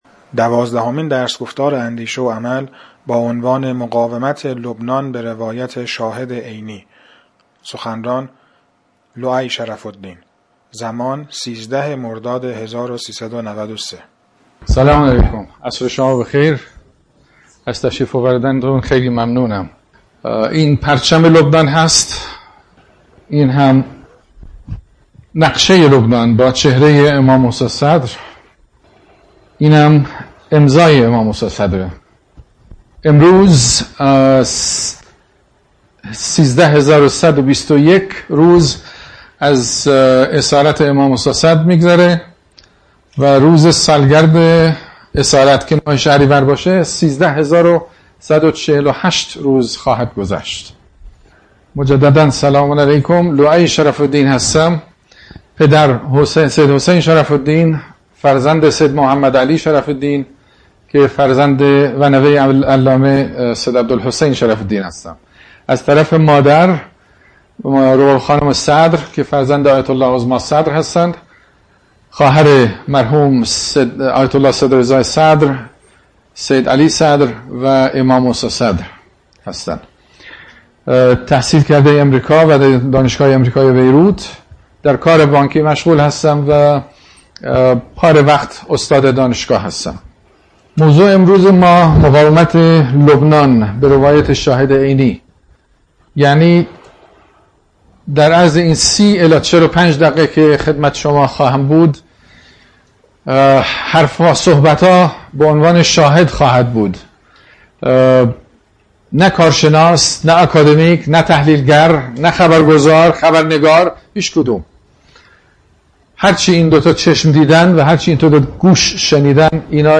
درس‌گفتار